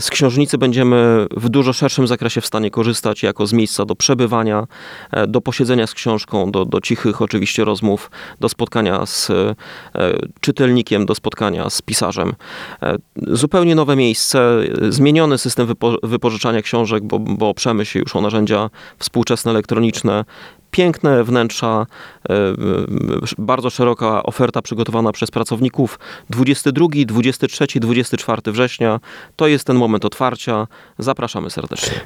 Stargardzka Książnica z powrotem otworzy się pod koniec września. Na wydarzenie zaprosił w Rozmowie Dnia Rafał Zając, prezydent Stargardu.